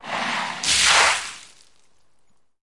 Ice » water ice cooler dumping a cooler full of ice onto concrete
描述：Dumping a cooler full of ice cubes onto concrete. Recorded with a Tascam DR40.
标签： cold wet percussive splash concrete water ice dumped dumping icecube dump crash icecubes impact
声道立体声